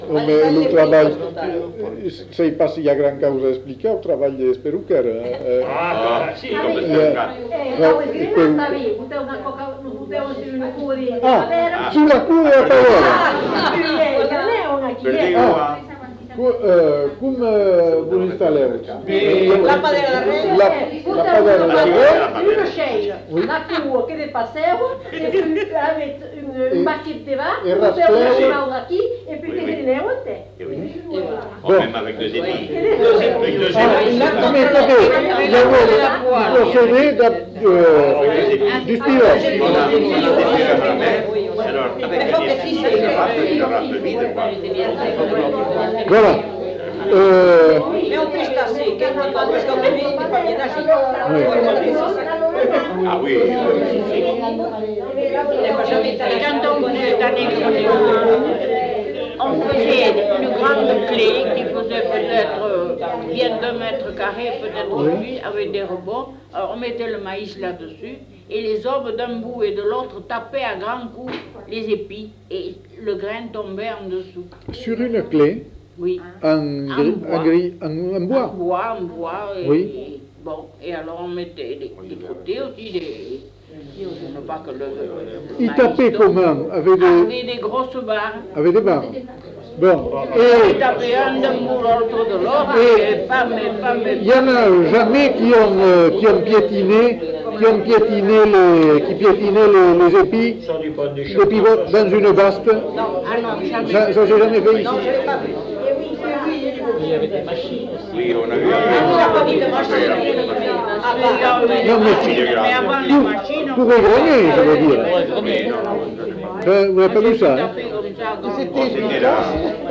Lieu : Bazas
Genre : témoignage thématique
archives sonores en ligne Contenu dans [enquêtes sonores] Témoignage sur la culture du maïs